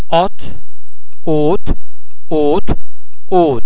The French [ o ] sound can also be almost as open as the vowel sound in English words like otter, lot.
o_hotte.mp3